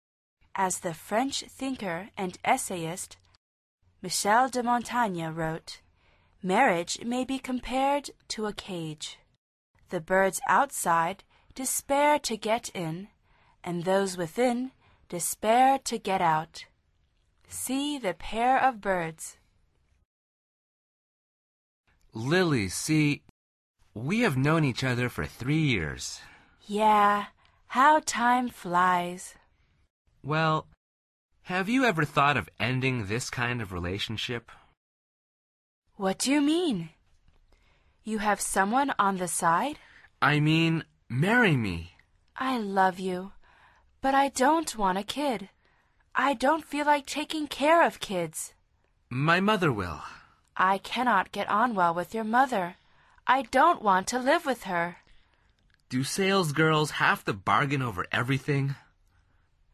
dialog1.mp3